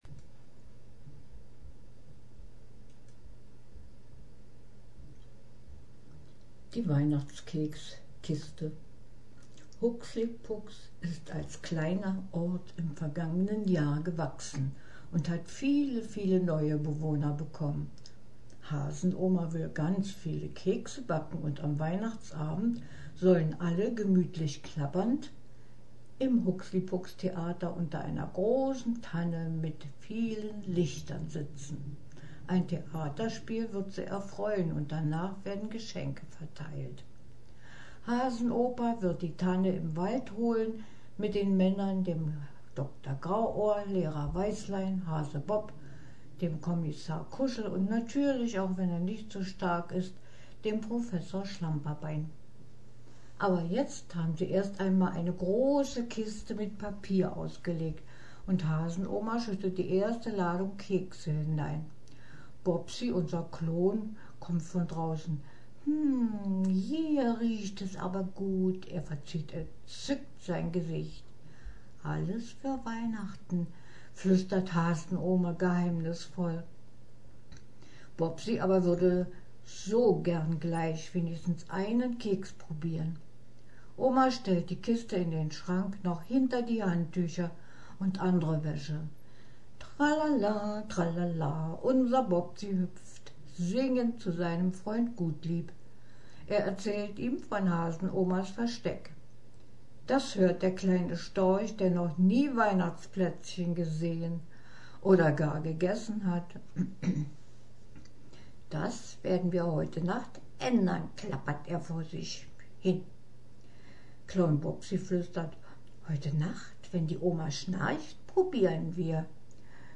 (vorgelesen von